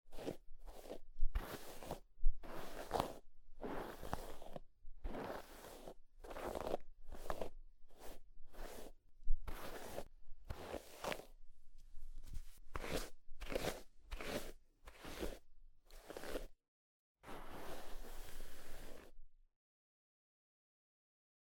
На этой странице вы найдете подборку звуков расчесывания волос – от мягких, едва слышных движений до четких, ритмичных проводок гребнем.
Звук шелеста щетки по волосам